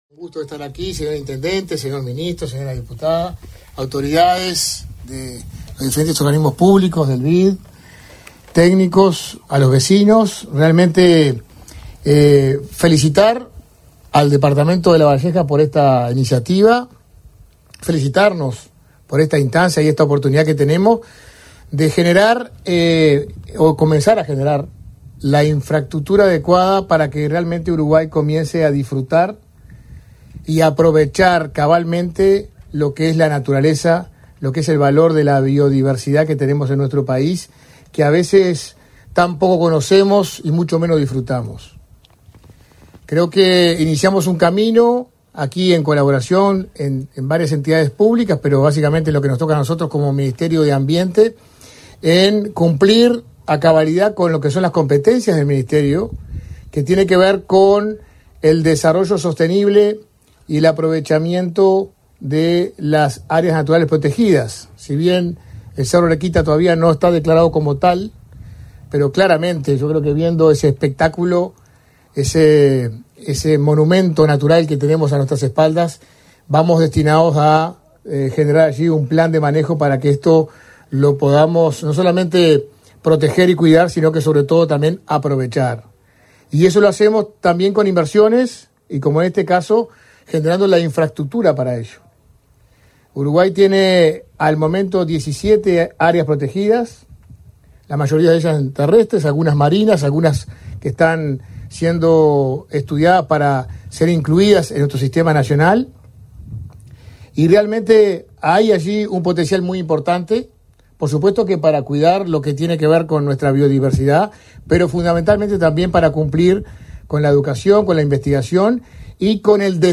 Conferencia de prensa por la inauguración de motorcamping en el Parque Arequita de Lavalleja
Participaron del evento, el ministro Tabaré Viera y el subsecretario de Ambiente, Gerardo Amarilla, entre otras autoridades.